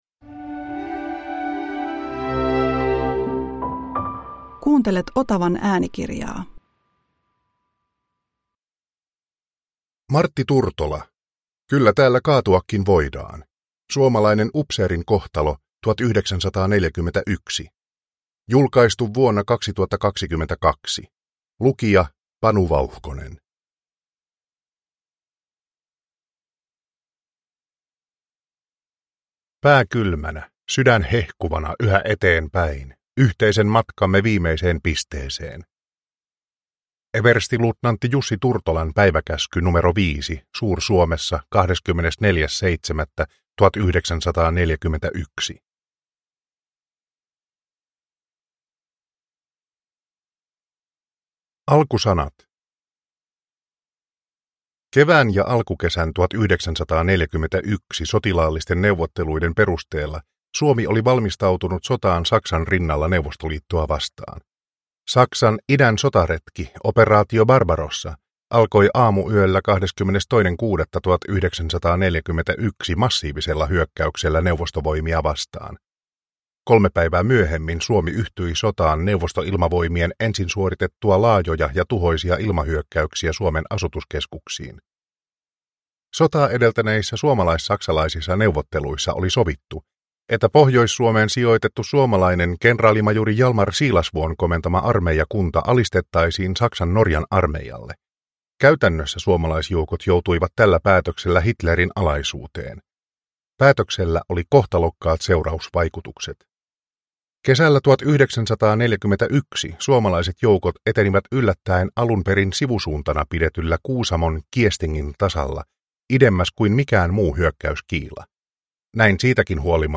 Kyllä täällä kaatuakin voidaan – Ljudbok – Laddas ner